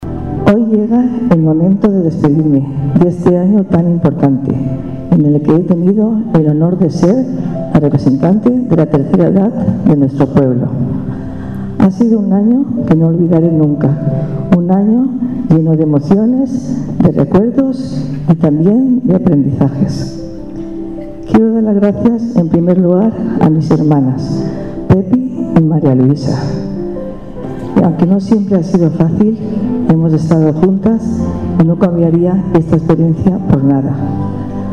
La 33ª edición de la Semana Cultural de la Asociación de Jubilados y Pensionistas “11 de septiembre” de Pinoso arrancó este jueves en el auditorio municipal.